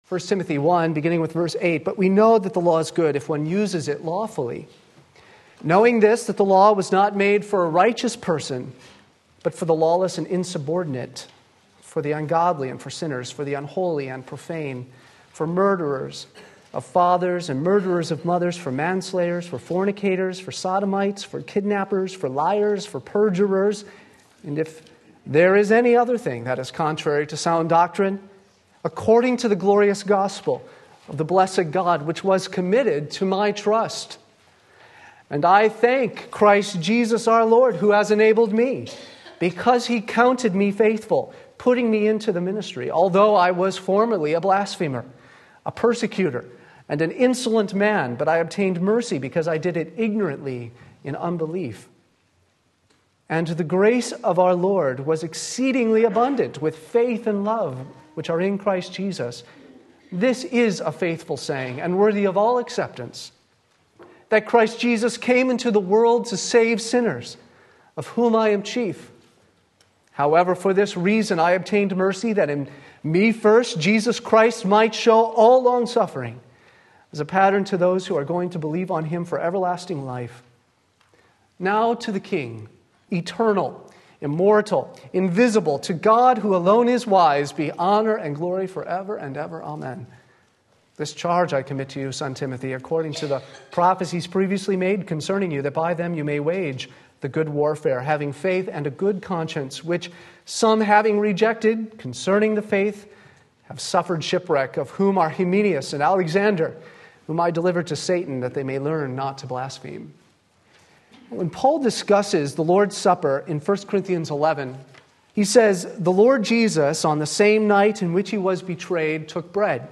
Sermon Link
Grace Abounding to the Chief of Sinners 1 Timothy 1:12-17 Sunday Morning Service